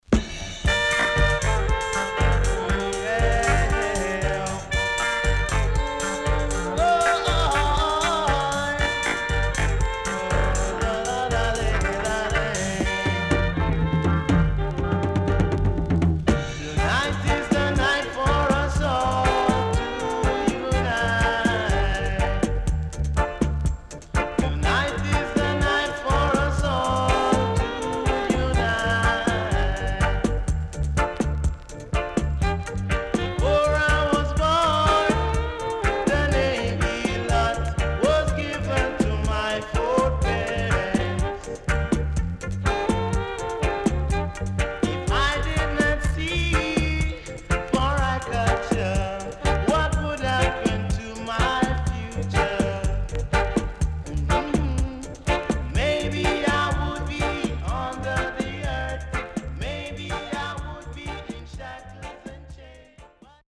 SIDE A:少しノイズ入りますが良好です。
SIDE B:少しノイズ入りますが良好です。